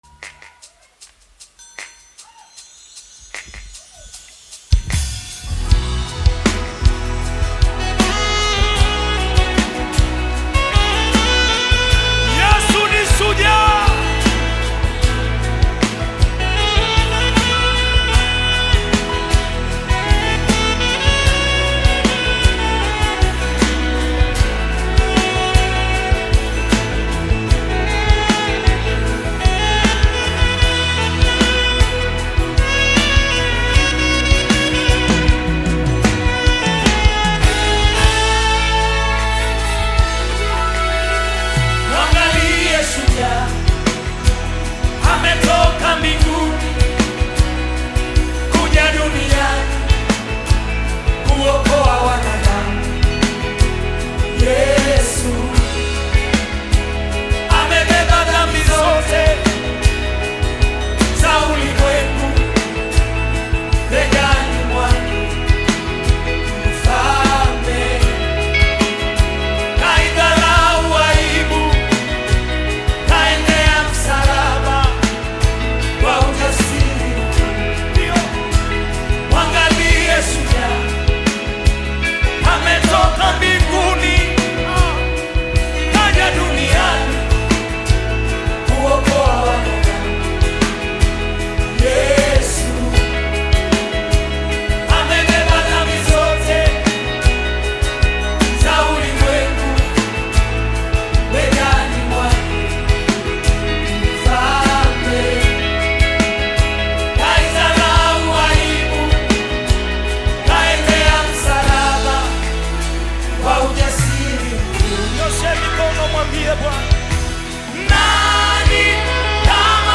Tanzanian gospel singer and songwriter
worship song
African Music